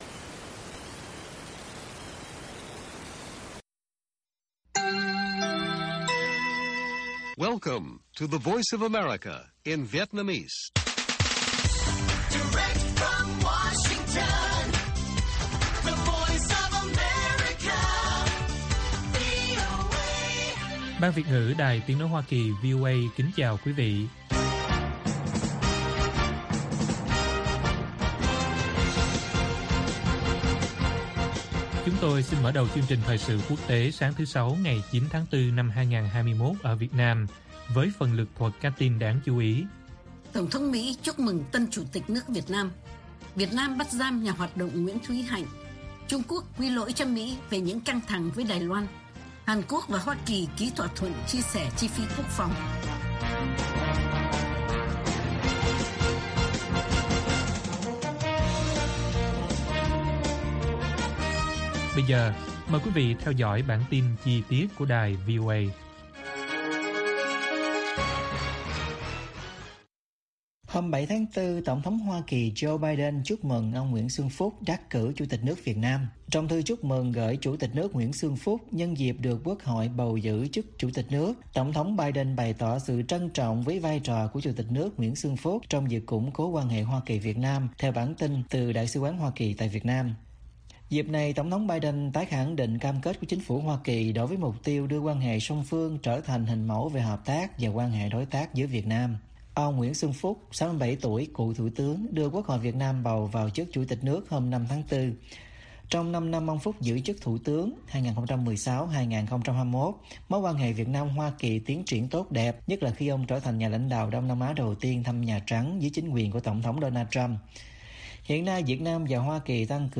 Bản tin VOA ngày 9/4/2021